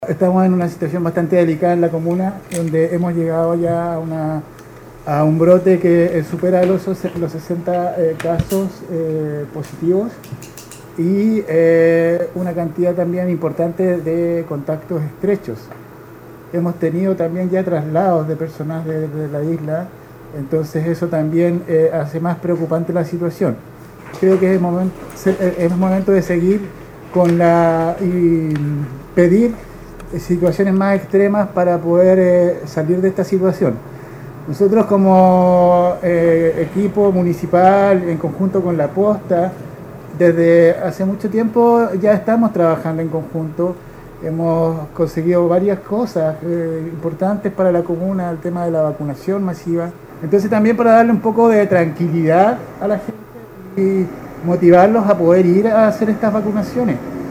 Un punto de prensa precisamente realizaron en conjunto, para dar a conocer la situación a la población, como lo expresó el alcalde (S) de Guaitecas, Enrique Higueras.
06-ALCALDE-GUAITECAS.mp3